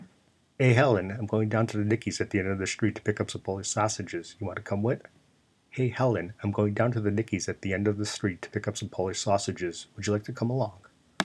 Here’s the “sausage” example.
The first is me talking quickly in my Chicago accent.
The “nasal” sound is not part of my accent, but rather the quality of my voice.
I always sound like I’m stuffed up, even when I’m not.)
sausage.m4a